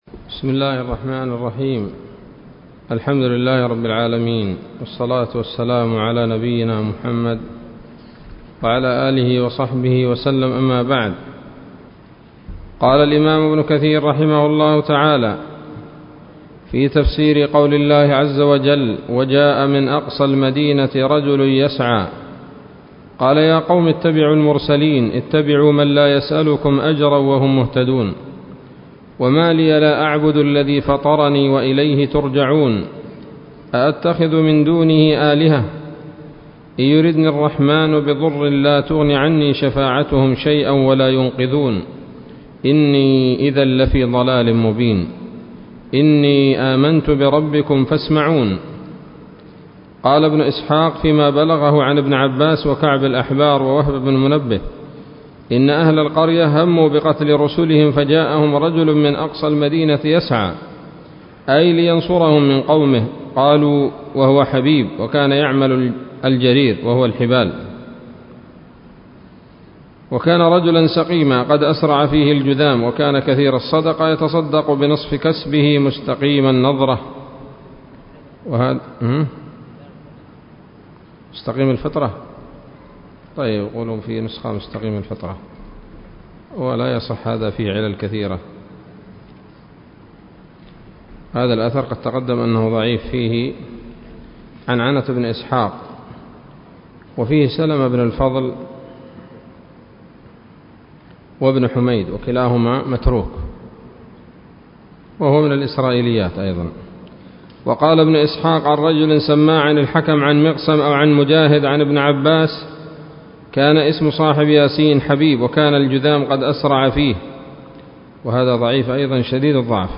الدرس الخامس من سورة يس من تفسير ابن كثير رحمه الله تعالى